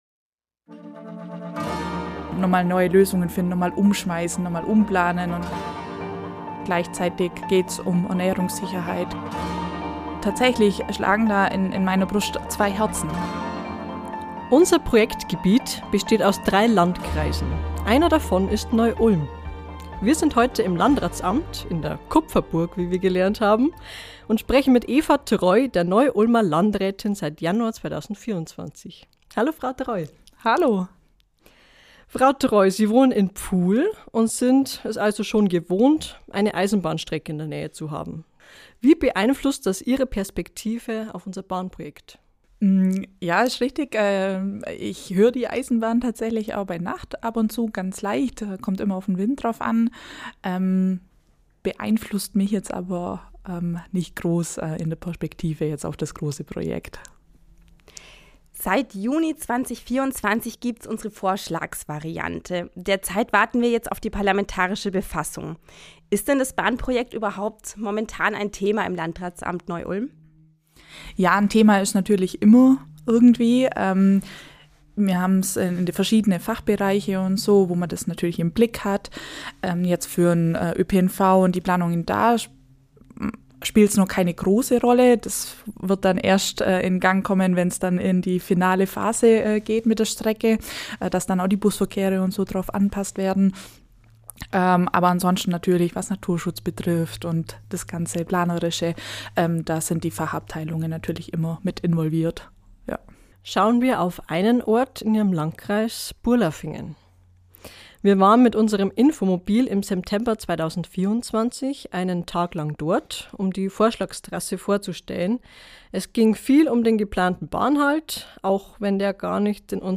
Ein Gespräch über Balanceakte, lokalpolitische Dynamiken und die Frage, ob Fortschritt immer Flächen kosten muss.